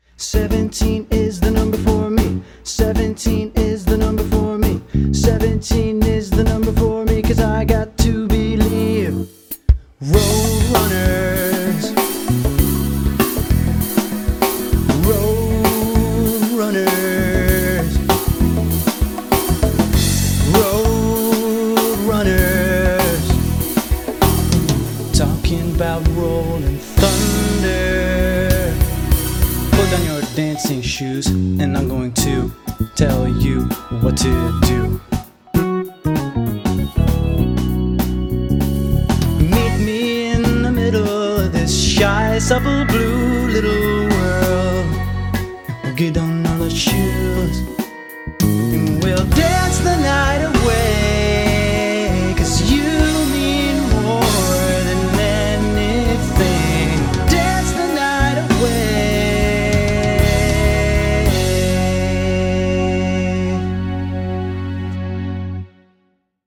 BPM98
Audio QualityPerfect (High Quality)
Such a bop for when you want to head out to a dance party.